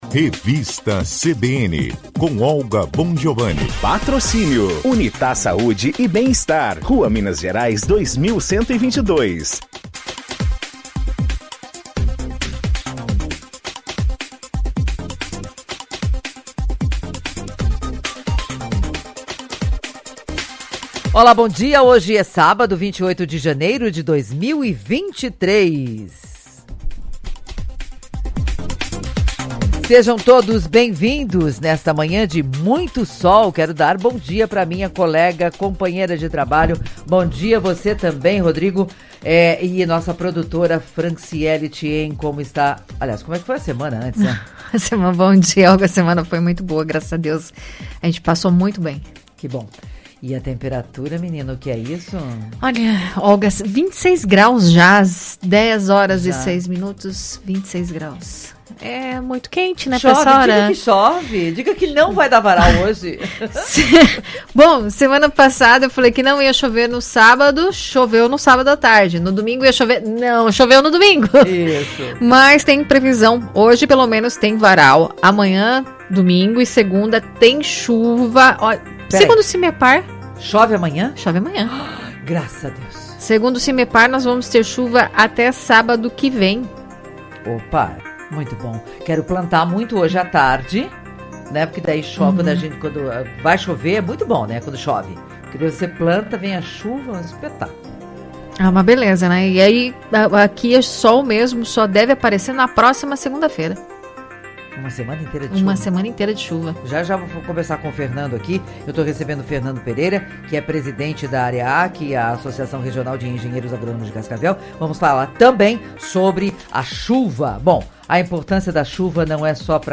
em entrevista à Revista CBN Cascavel fala sobre a abrangência da atuação do engenheiro agronômo e sobre os agrotòxicos.